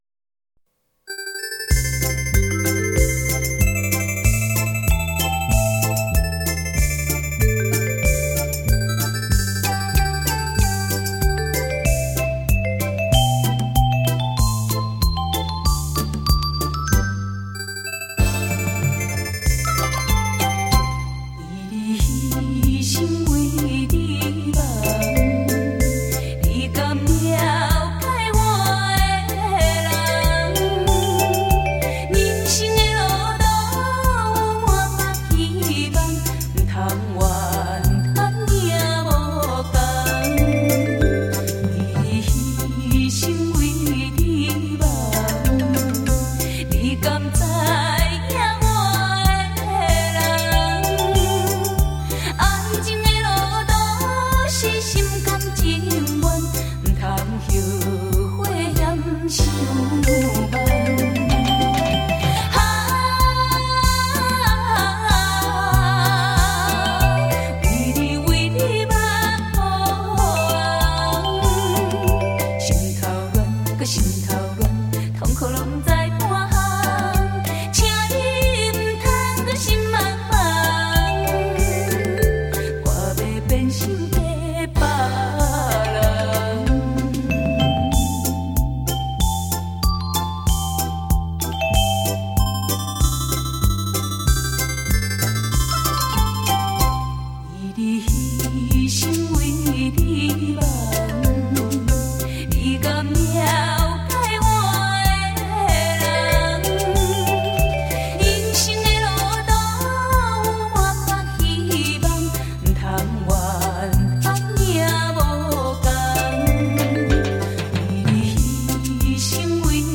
原声原味原纪录